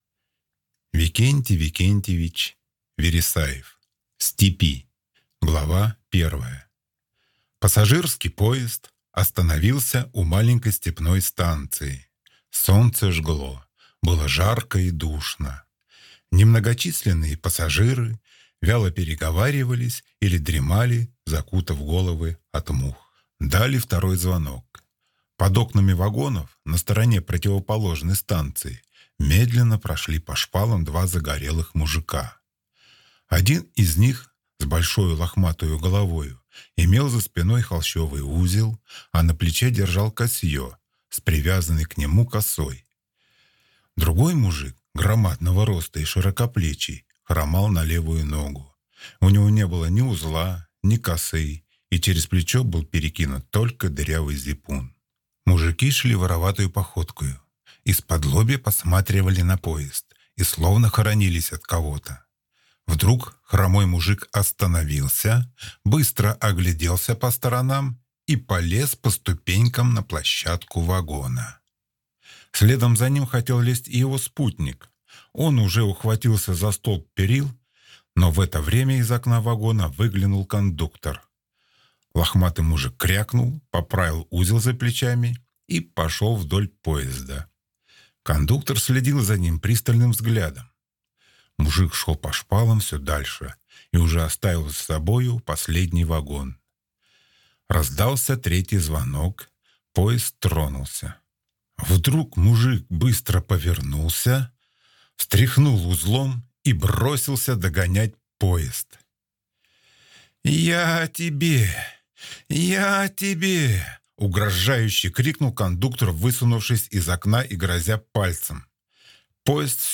Аудиокнига В степи | Библиотека аудиокниг